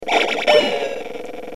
Hitting a teammate
Category: Sound FX   Right: Personal
Tags: Photon Sounds Photon Sound Photon clips Sci-fi Sound effects